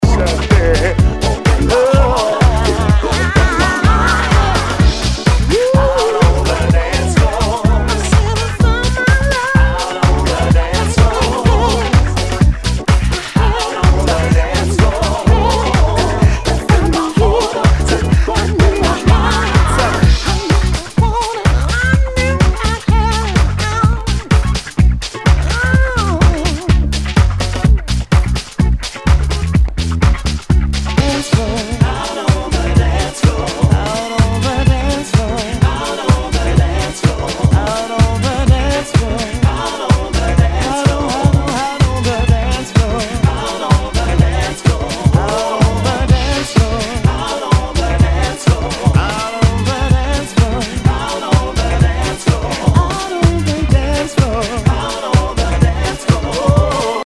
HOUSE/TECHNO/ELECTRO
ナイス！ヴォーカル・ハウス！！
盤に少し歪みあり
[VG ] 平均的中古盤。スレ、キズ少々あり（ストレスに感じない程度のノイズが入ることも有り）